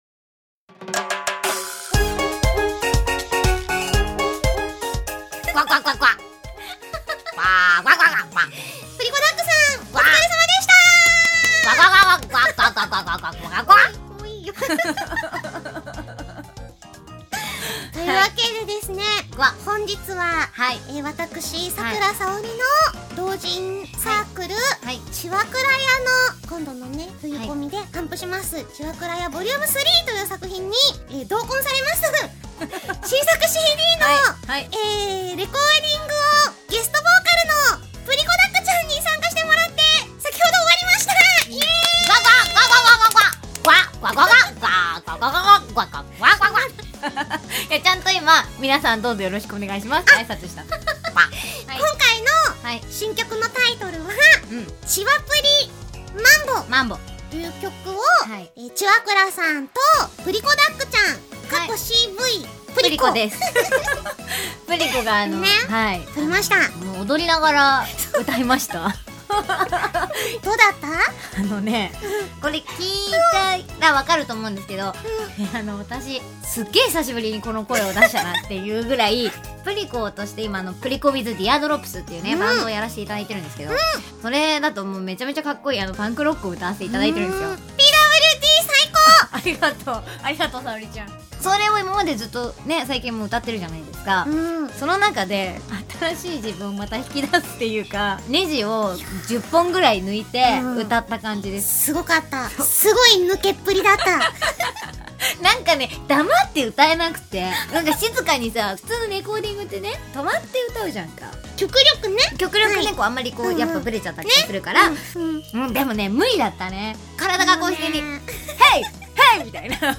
先日、ラジオでも流したのですが、
メッセージもあらためて公開！